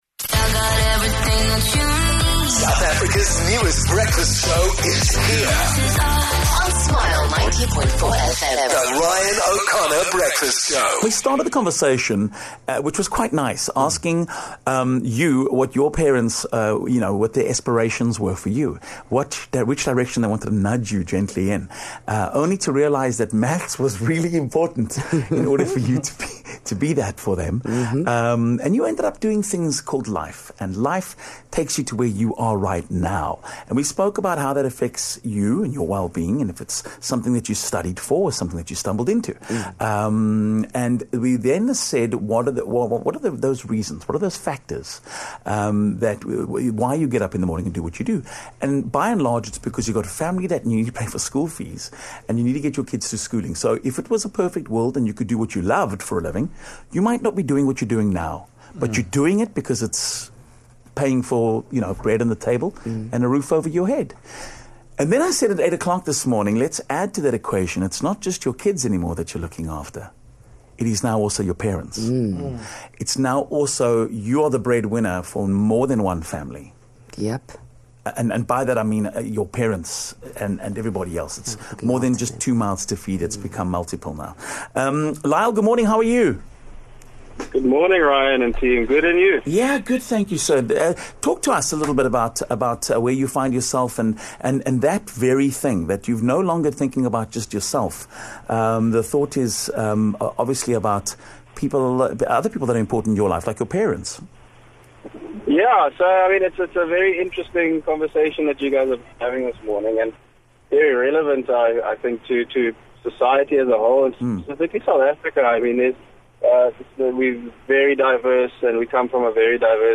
One listener called in and gave us an interesting perspective on something that a large portion of the population face - when our parents start relying on us in our respective careers.